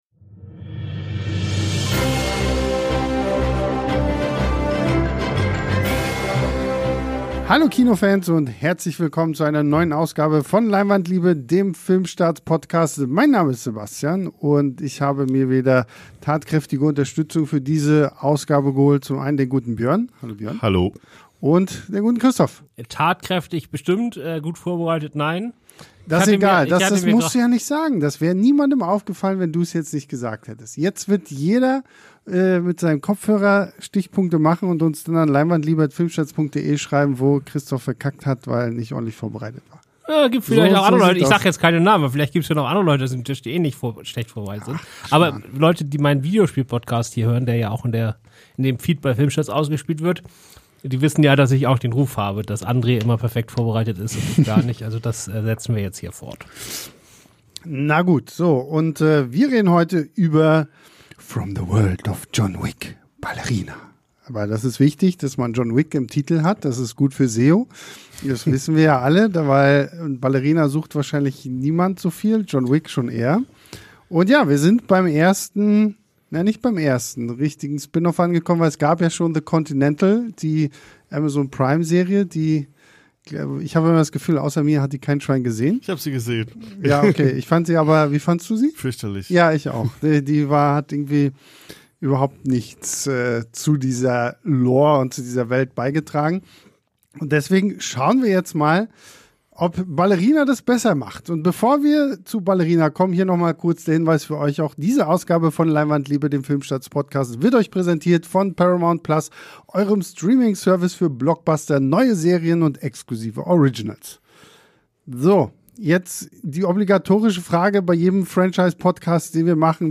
Ohne Skript, aber mit Liebe und Fachwissen.